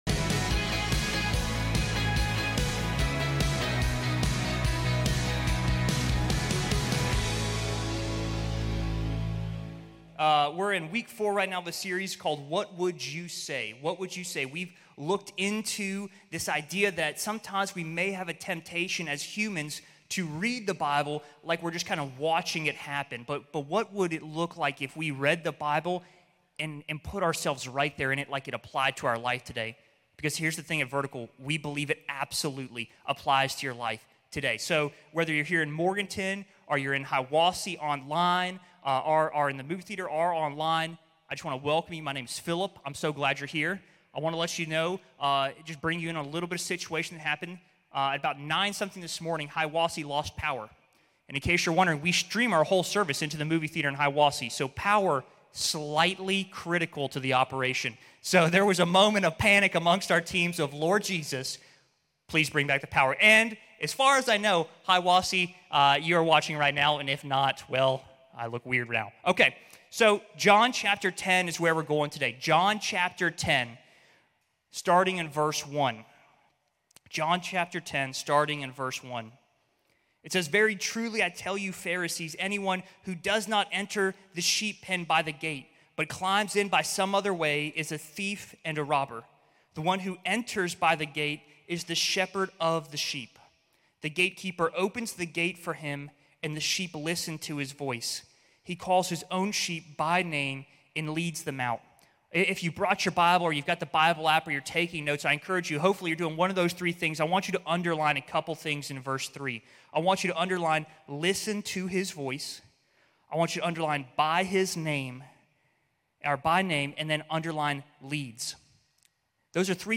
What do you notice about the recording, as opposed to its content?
The Good Shepard | Vertical Church of the Mountains